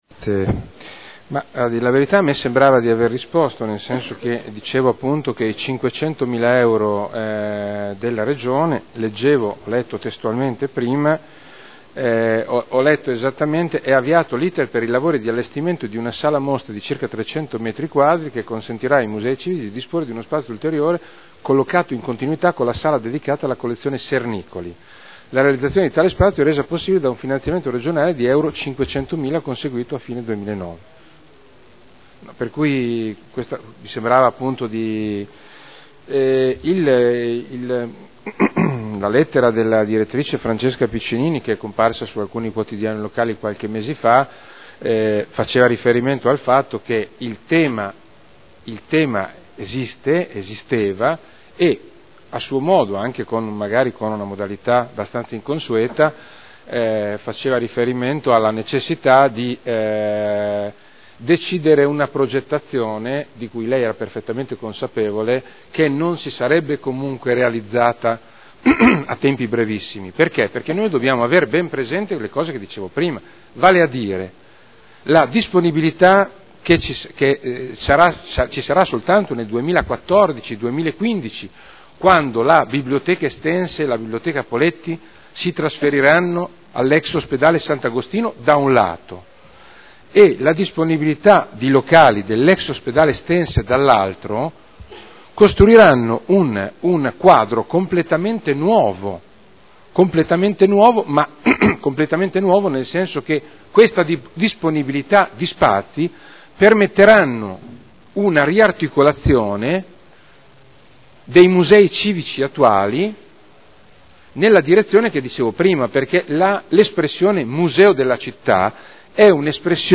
Seduta del 28/02/2011.